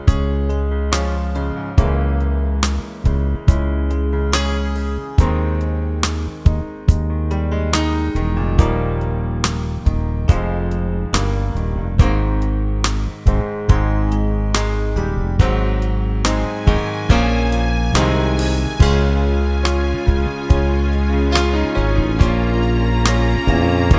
No Fade In Chord Pop (1960s) 3:48 Buy £1.50